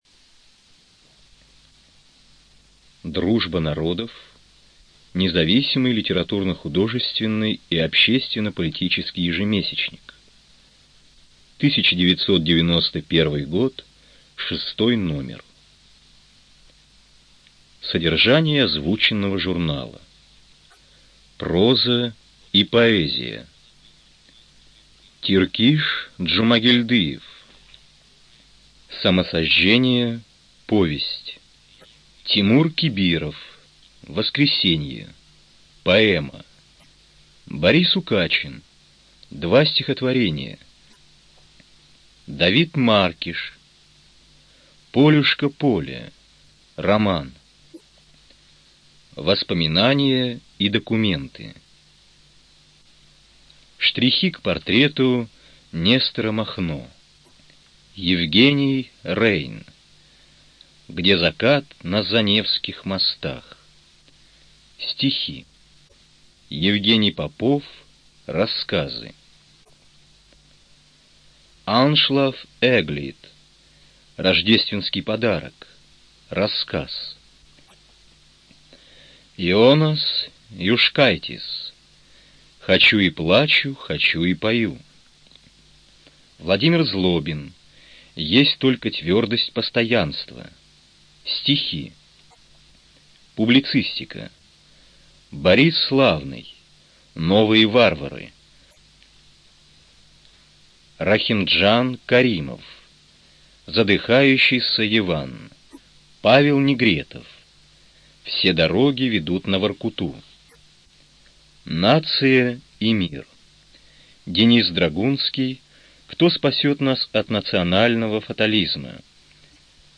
Студия звукозаписиКругозор